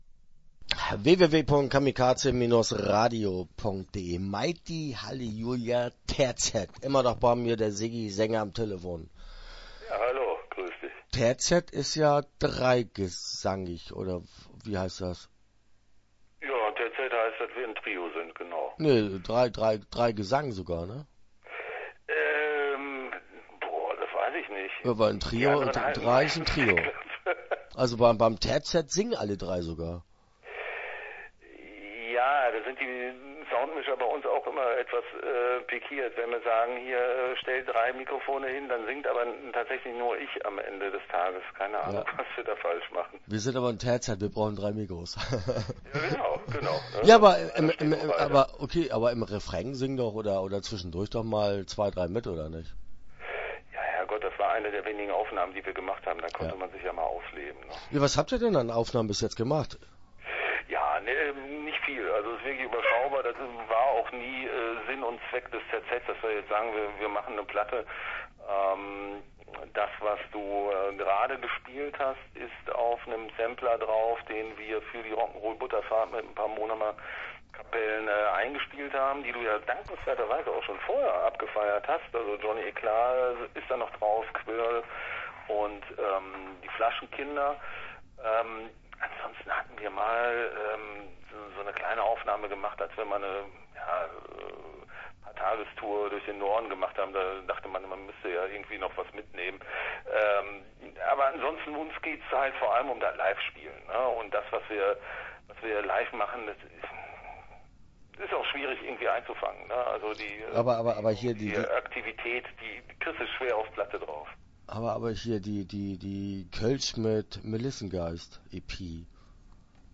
Mighty Hallelujah Terzett - Interview Teil 1 (9:09)